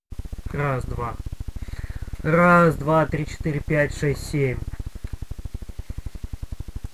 Ещё одну проблему обнаружил, это уже серъёзно - не работает нормально запись через WDM (привет стримерам и скайпу).